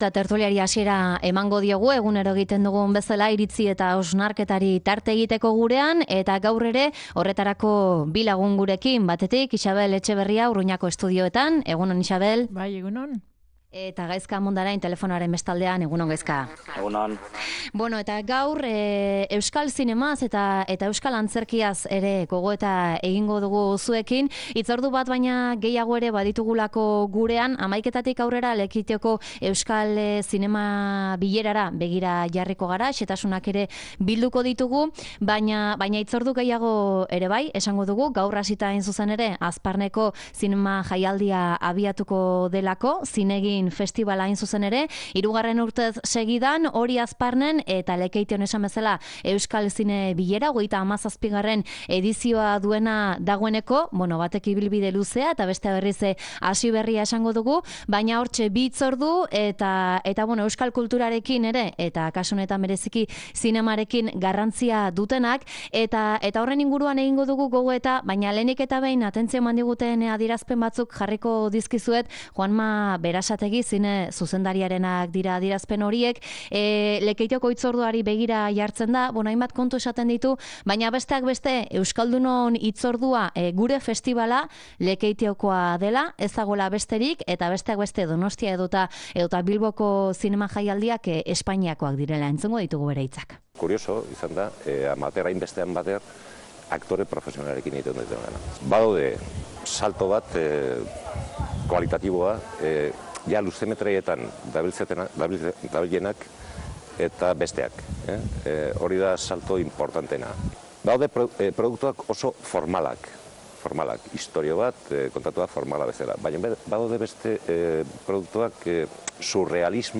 Tertulia: euskal zinema eta antzerkia